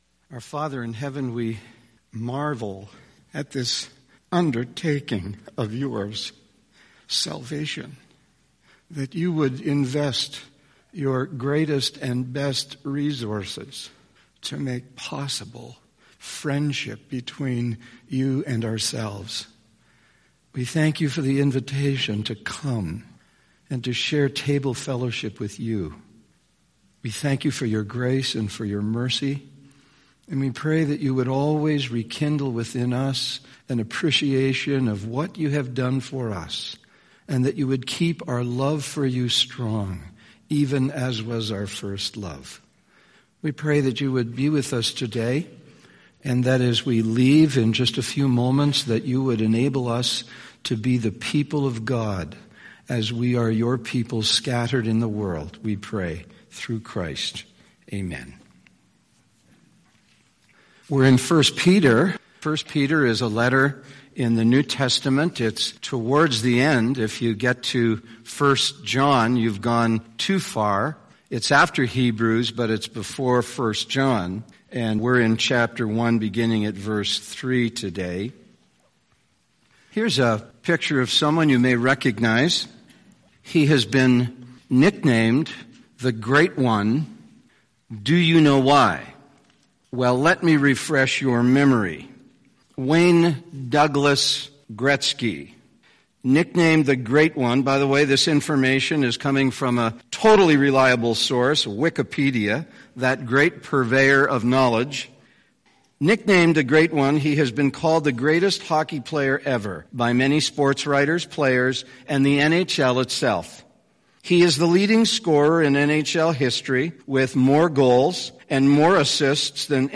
Sermons | Balmoral Bible Chapel